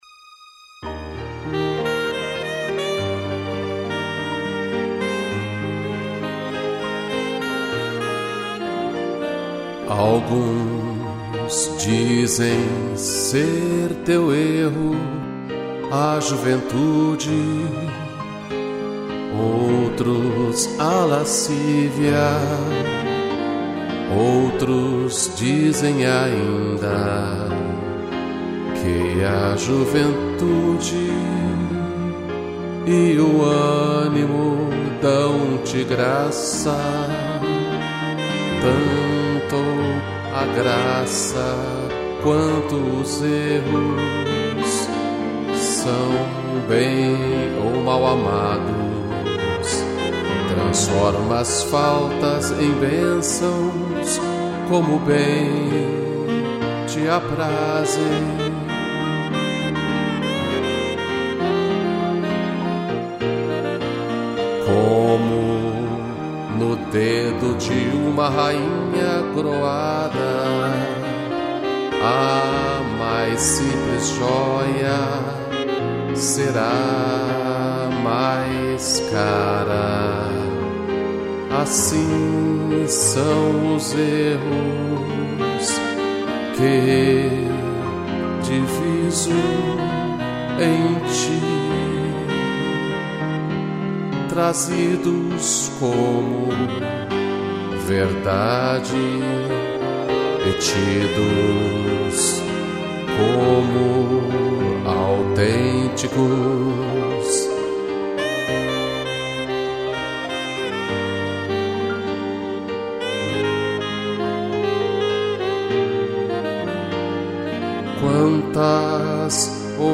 2 pianos, cello, violino e sax